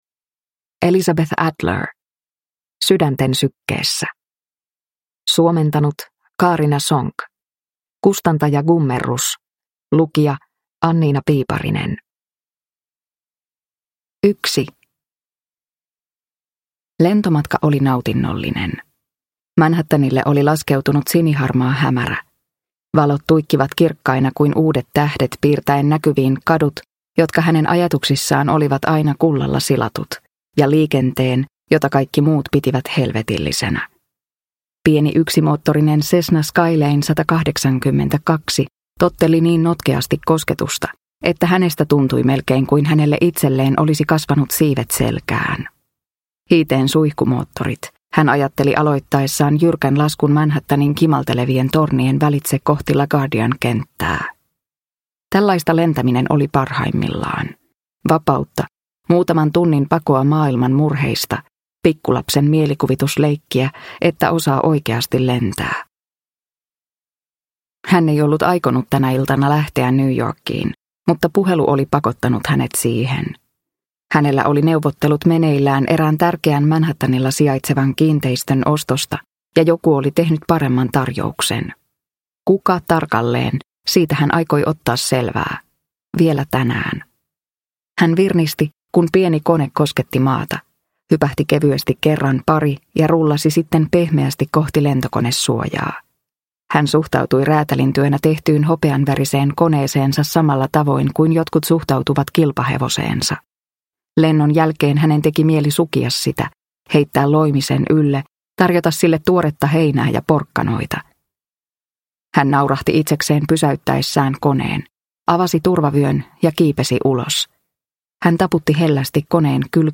Sydänten sykkeessä – Ljudbok – Laddas ner